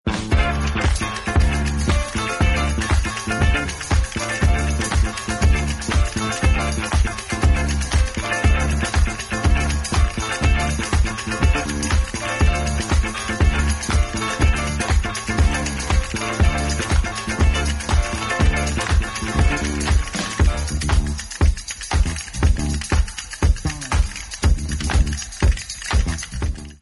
Des origines Disco